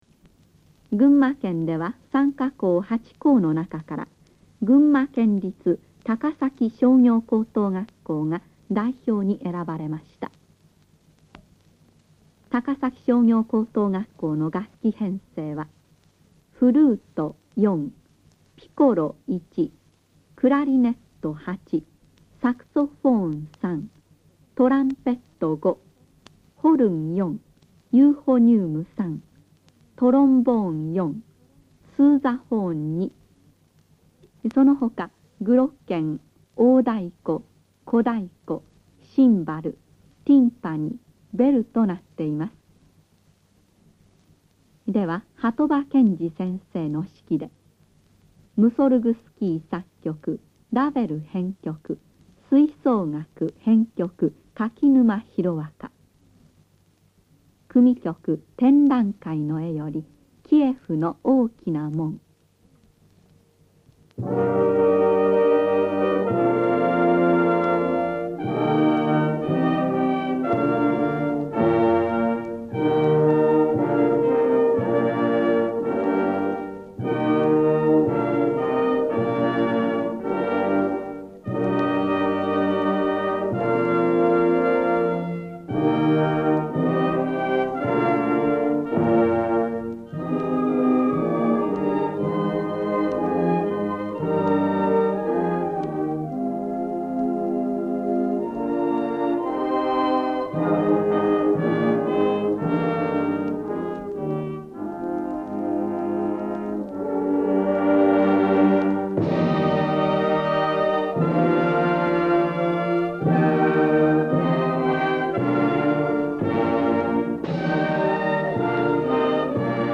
1966 NHK学校音楽コンクール 関東甲信越大会